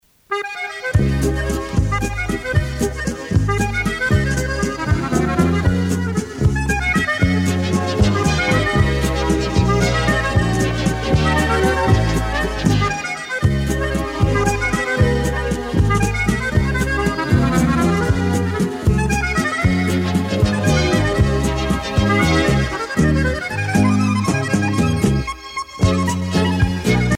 valse musette
Tempo Grande vitesse
Pièce musicale éditée